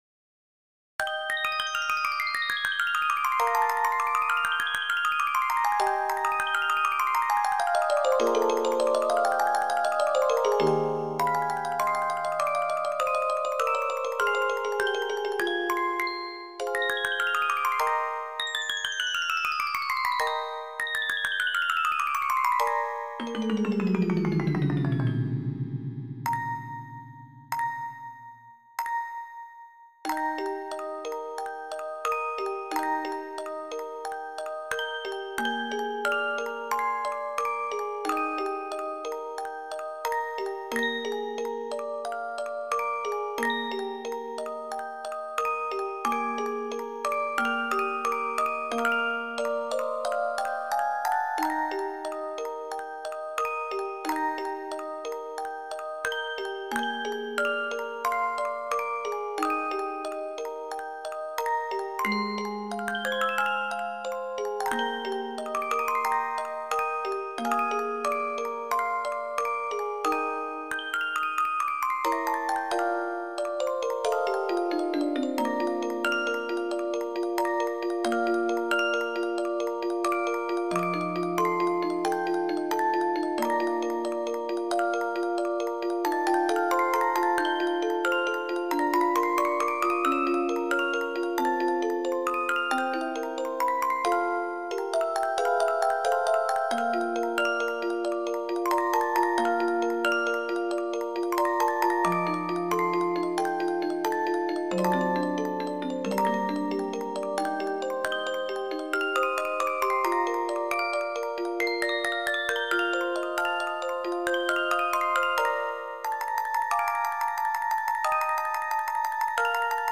クラシック曲（作曲家別）－MP3オルゴール音楽素材
オルゴール チェレスタ ミュージックボックス